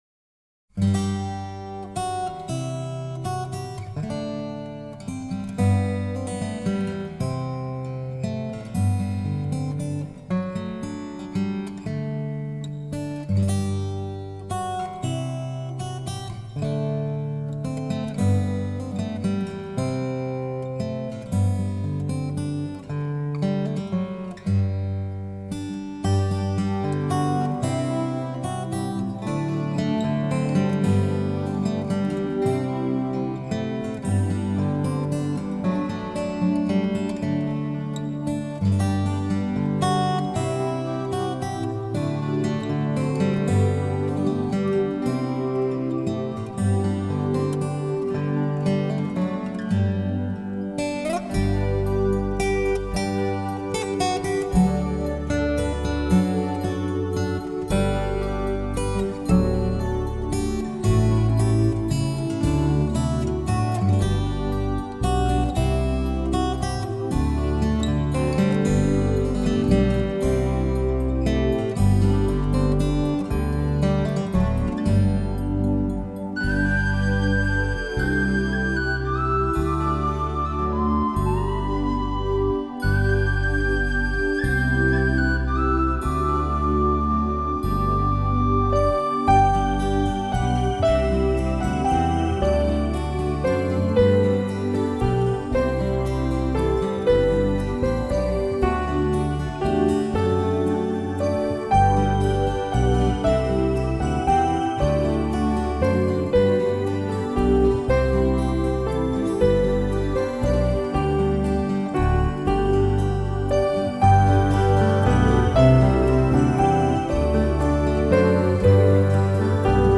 Disc . 1 動人演奏經典
[center]上傳之音輯僅低音質壓縮，請會員於下載試聽後即刪除檔案，收藏敬請購買原版權音碟．